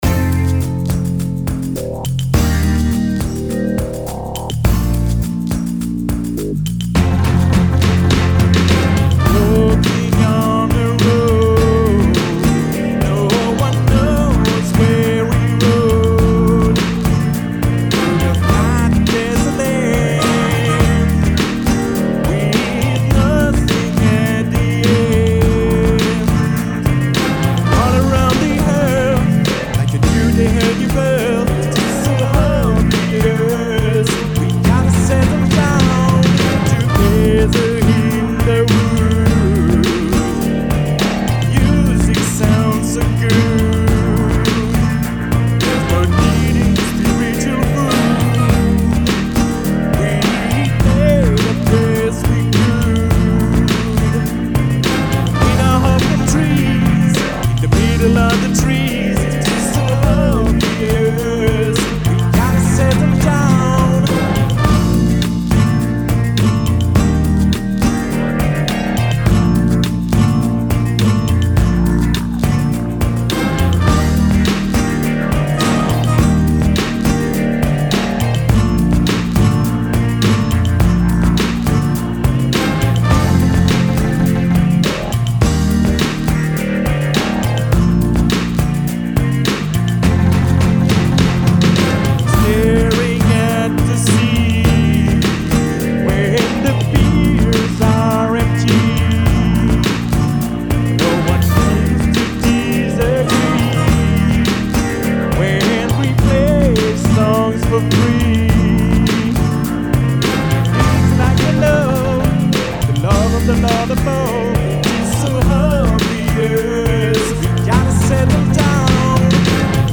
SoSouL orch.